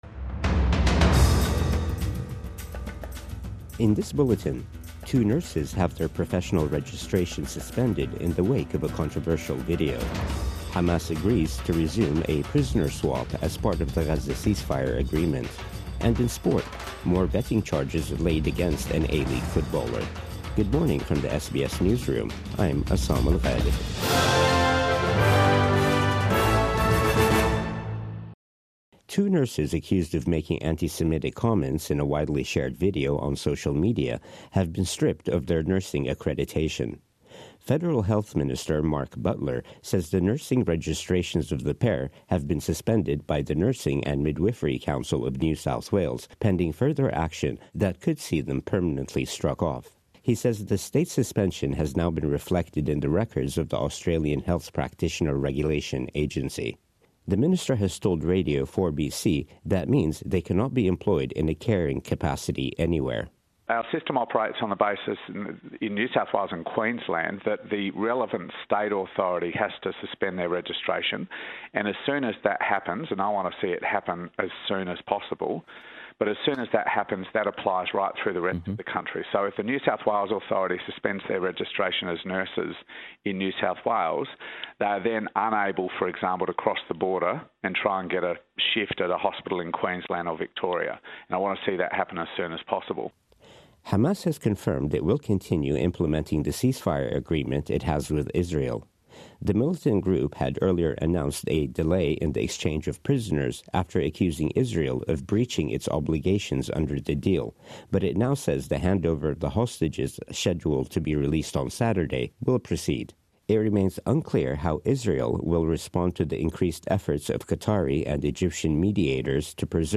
Cylone Zelia approaches WA as Cat 5 system | Morning News Bulletin 14 February 2025